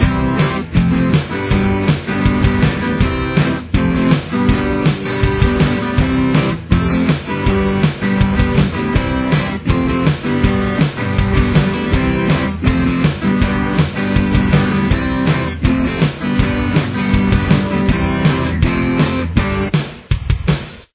Verse